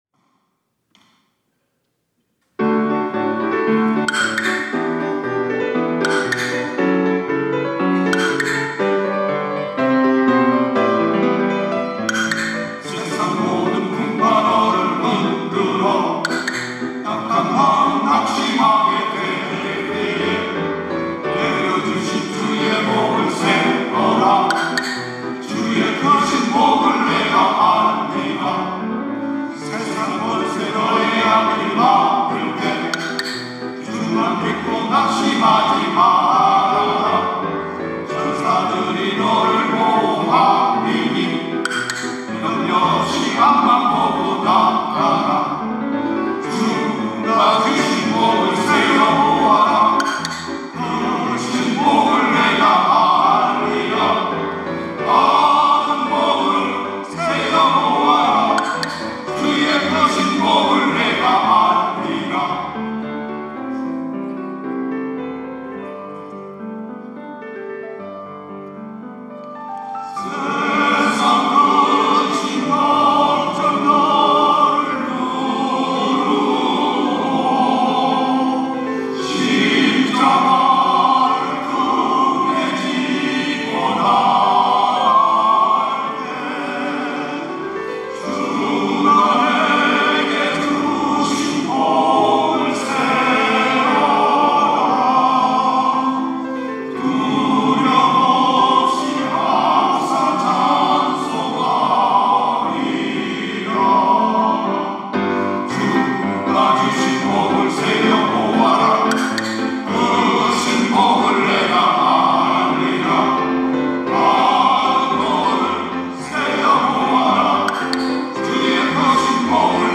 특송과 특주 - 받은 복을 세어 보아라
천안장로합창단